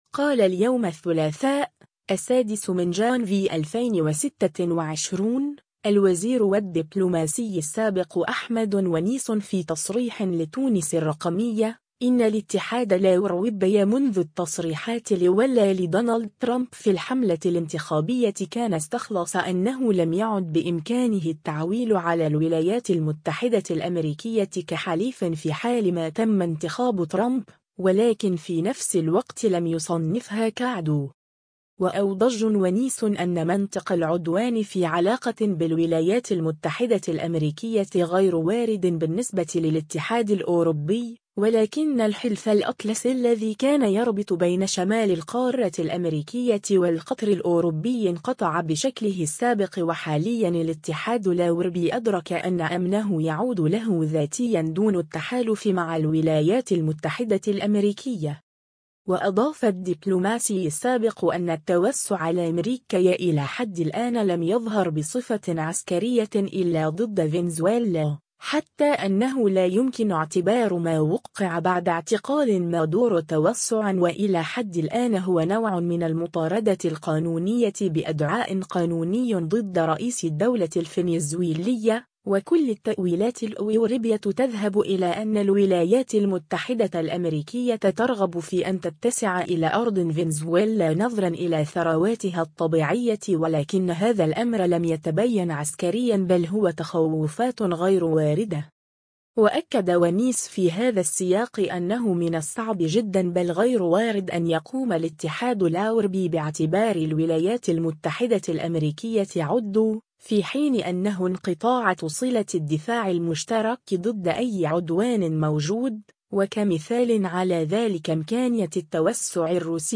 قال اليوم الثّلاثاء، 06 جانفي 2026، الوزير و الدّبلوماسي السابق أحمد ونيس في تصريح لتونس الرّقمية، إنّ الاتحاد الاوروبي منذ التصريحات الاولى لدونالد ترامب في الحملة الانتخابية كان استخلص أنّه لم يعد بإمكانه التعويل على الولايات المتّحدة الأمريكية كحليف في حال ما تمّ انتخاب ترامب، و لكن في نفس الوقت لم يصنّفها كعدو.